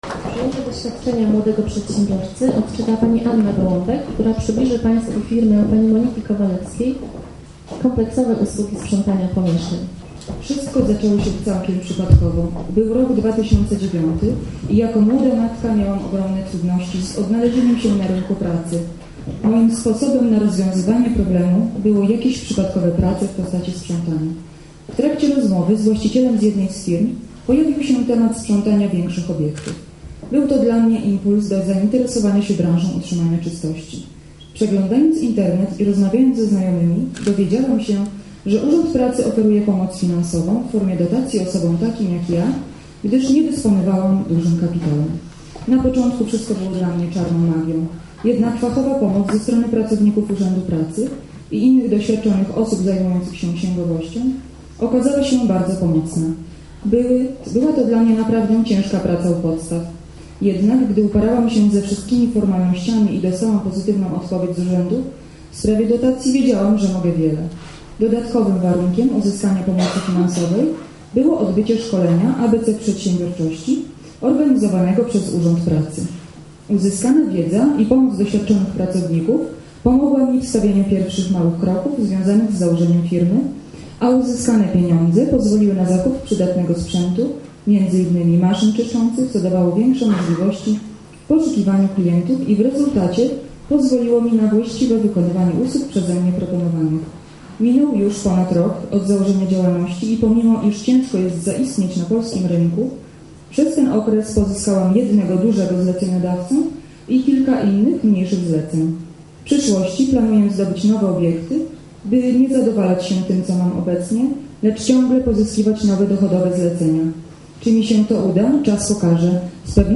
Bardzo ciekawym punktem było odczytanie wypowiedzi przedsiębiorców, którzy uzyskali pomoc z PUP na rozpoczęcie działalności, o ich pomyśle na biznes i jego realizacji.